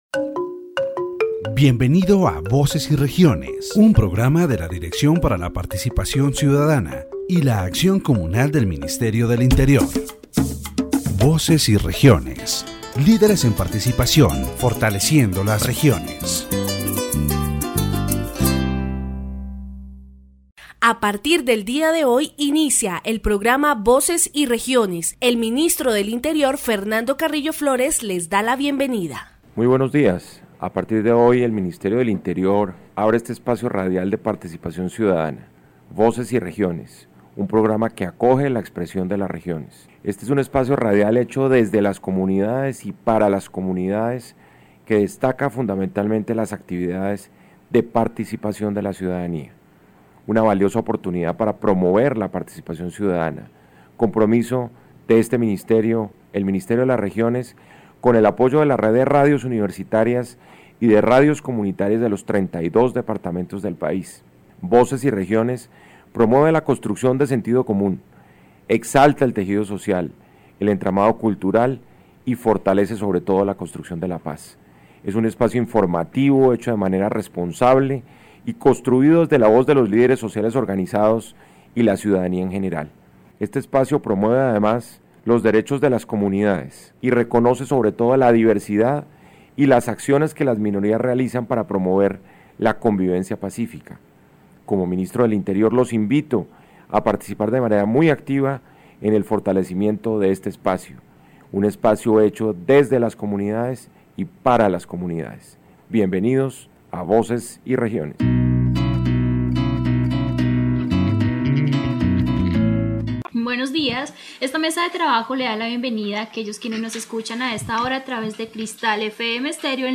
The Ministry of the Interior's radio program “Voces y Regiones” (Voices and Regions) is dedicated to promoting citizen participation and highlighting community activities in this area. In its first broadcast, the program addressed the importance of citizen participation processes, including social protest as a valid form of democratic participation.